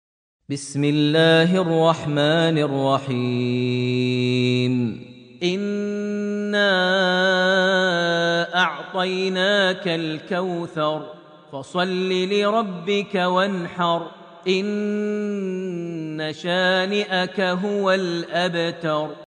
Surat Al-Kawthar > Almushaf > Mushaf - Maher Almuaiqly Recitations